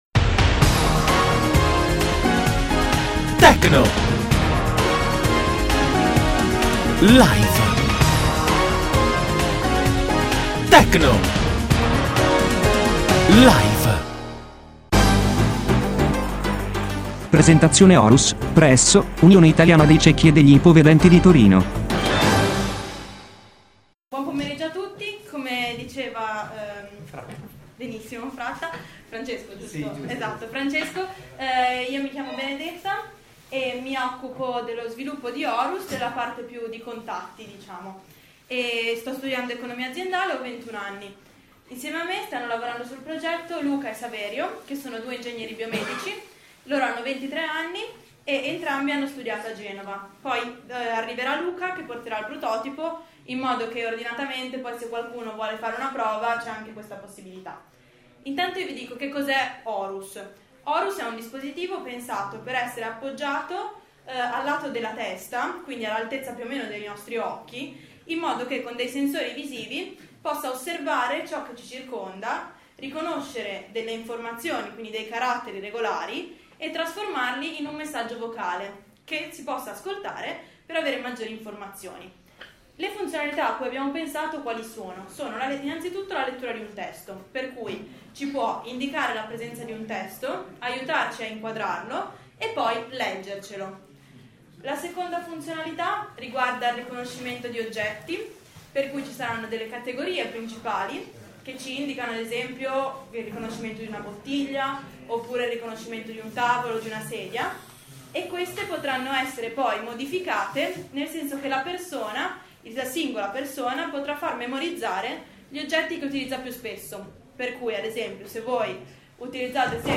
Si tratta della registrazione della prima parte dell'incontro tenutosi presso la sezione di Torino dell'Unione Italiana Ciechi e degli Ipovedenti, nel quale vengono illustrate le potenzialità della tecnologia Horus.